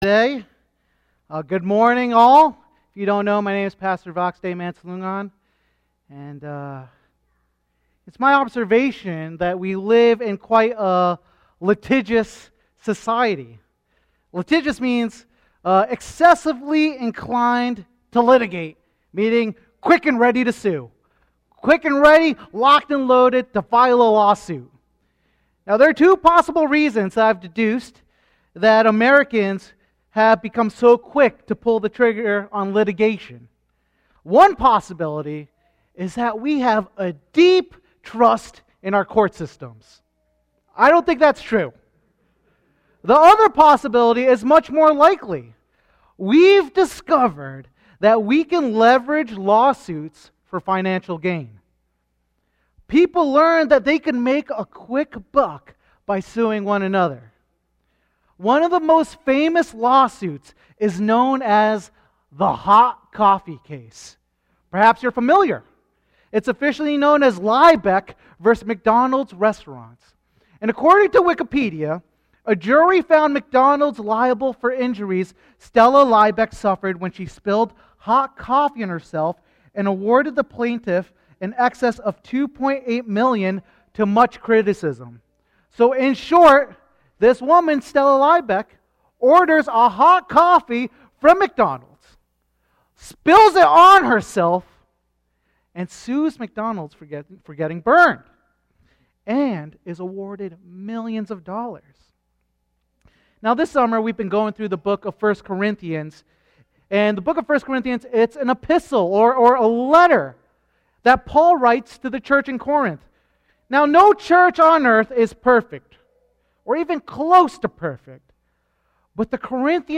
Sermons Archive - New Village Church